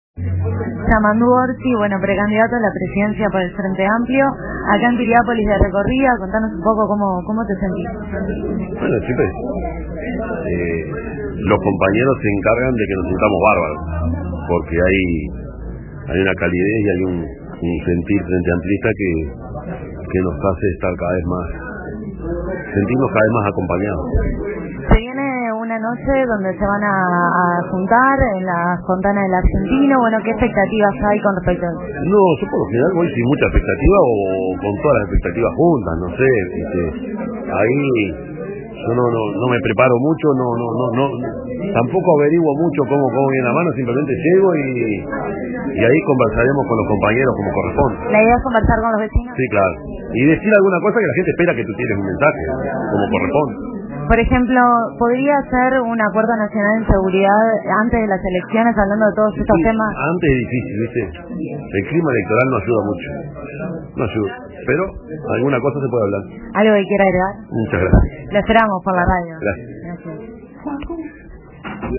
El precandidato del Frente Amplio, Yamandú Orsi, visitó Piriápolis este martes (04.06.2024). En declaraciones a RADIO RBC, se refirió a la posibilidad de alcanzar un acuerdo nacional sobre seguridad.